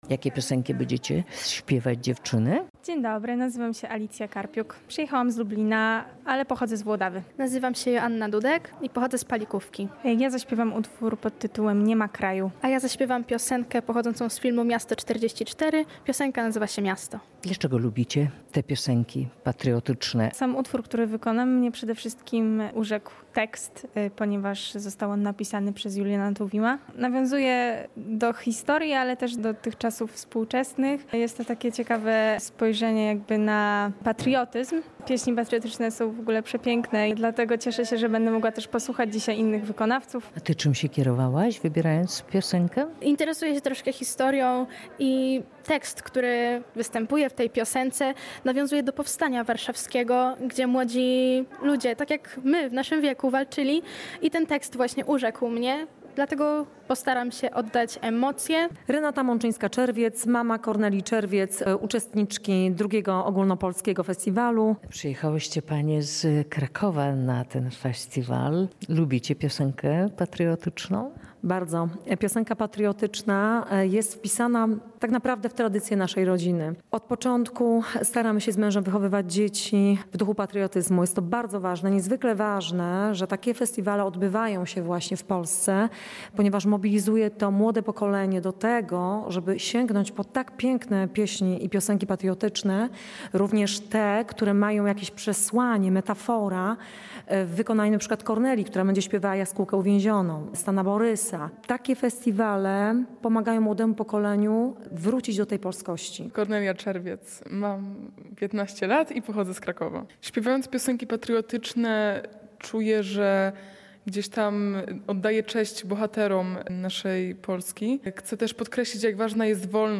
Młodzi wykonawcy mówią, ze piosenki patriotyczne bliskie są ich sercu.